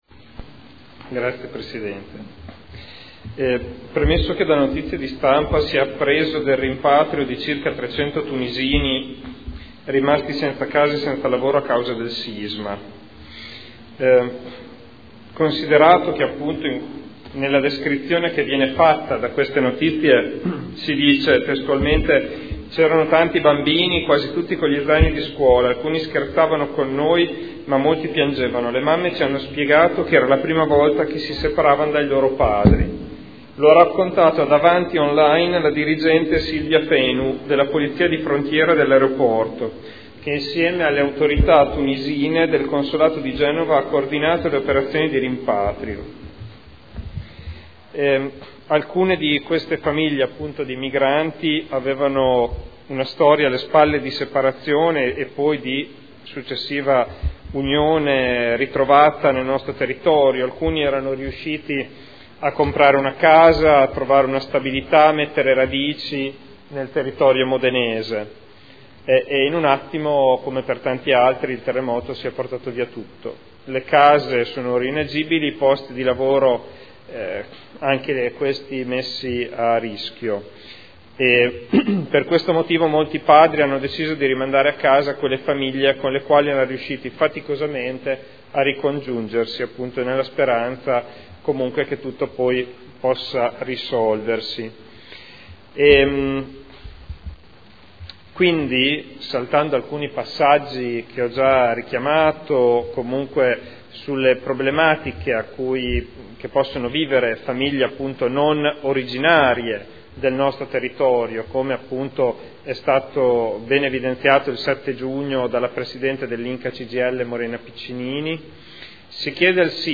Federico Ricci — Sito Audio Consiglio Comunale